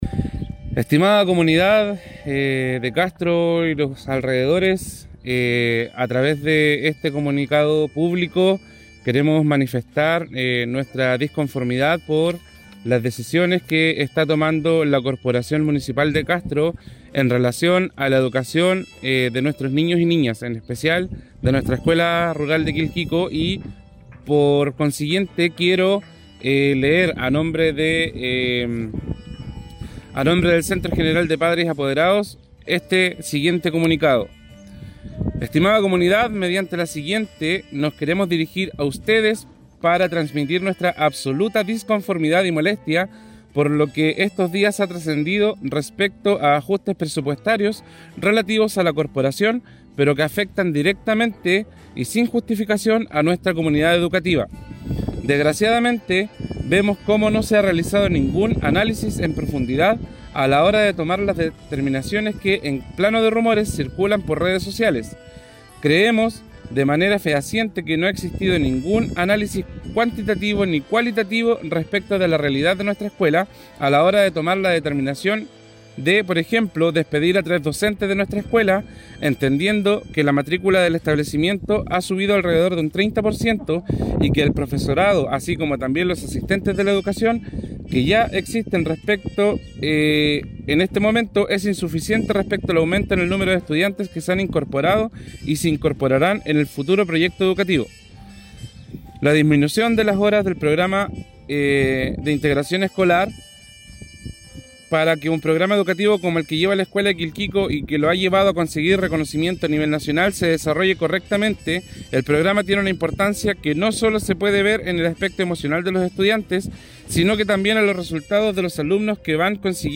18-CASTRO-DECLARACION-ESCUELA-DE-QUILQUICO.mp3